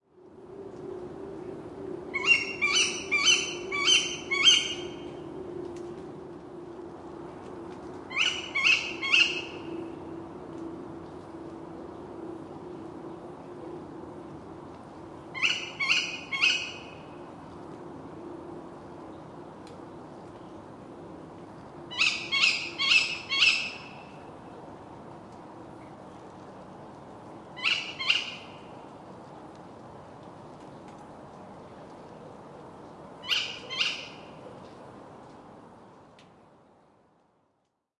Tawny Owl in Molkom, Sweden
描述：A tawny owl I recorded while being outside in the middle of the night in Molkom, Sweden. Recorded with my iPhone 5s.
标签： TawnyOwl Tawny Night Sweden Owl
声道立体声